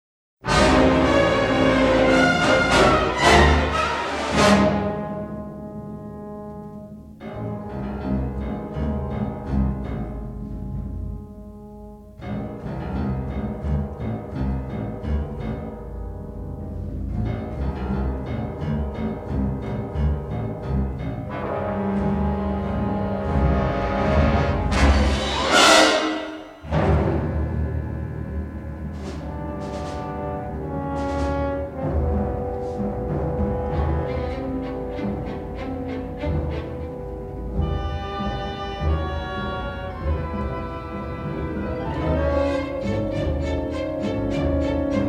and a classic symphonic score.